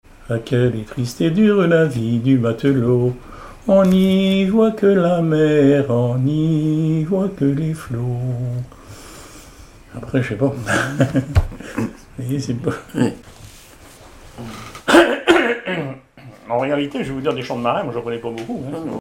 Genre strophique
Témoignages sur le cycle calendaire et des extraits de chansons maritimes
Pièce musicale inédite